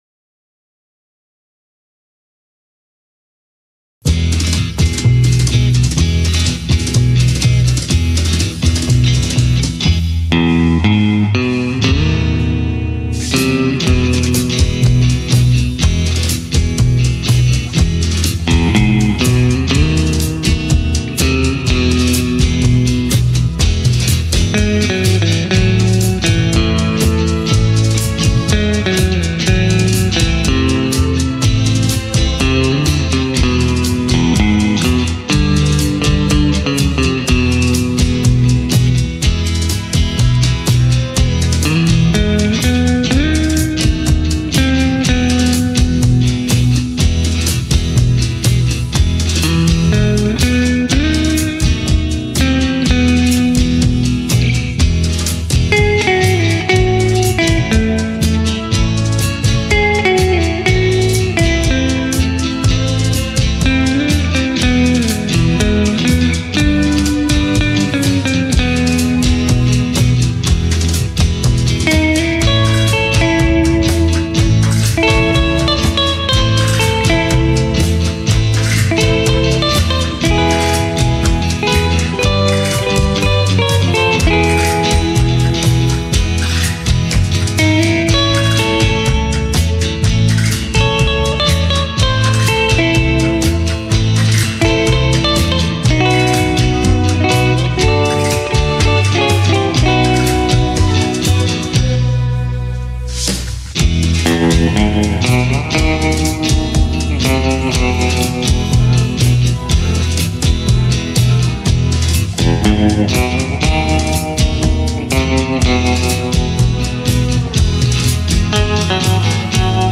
Black Jazzmaster - Doing all the guitar work